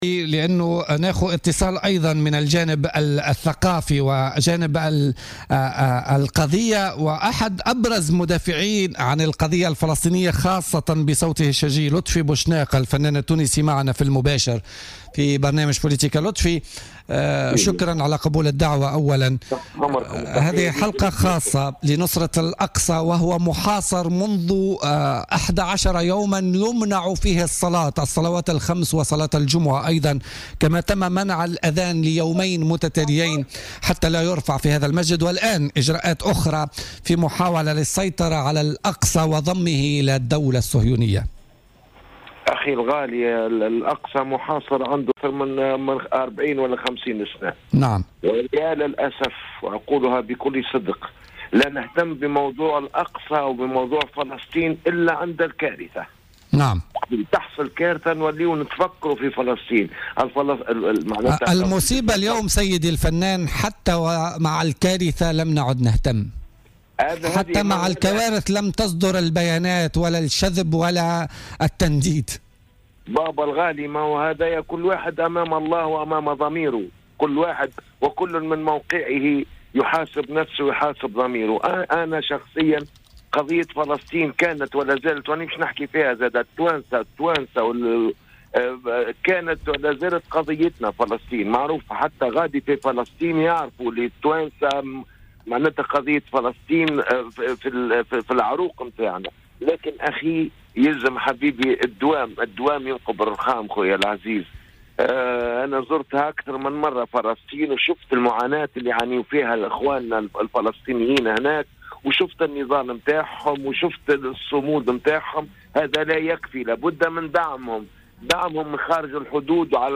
أكد الفنان لطفي بوشناق في مداخلة له اليوم الاثنين 24 جويلية 2017 في بولتيكا أن الأقصى محاصر منذ 40 أو 50 عاما ولكن المؤسف بالفعل هو أن لايأتي الاهتمام بفلسطين وبالقضية إلا عندما تحدث الكارثة على حد قوله.